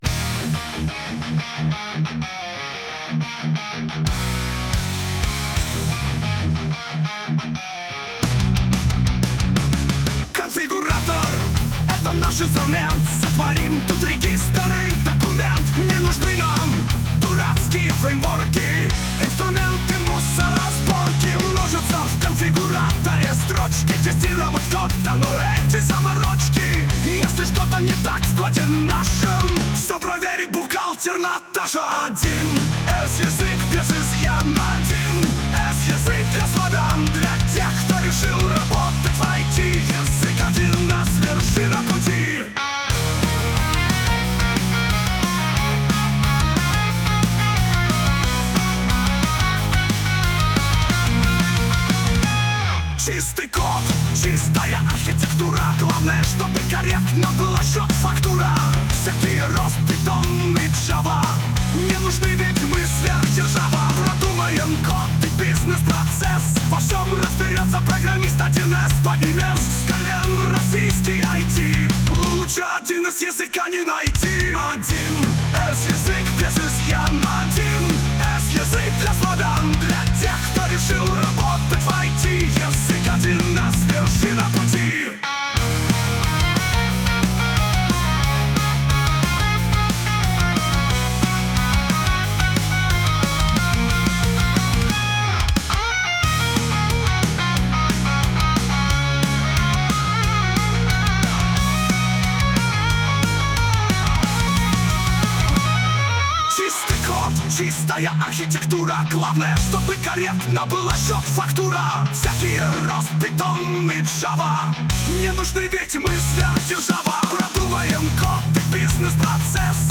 (25) вторая веселая ;-)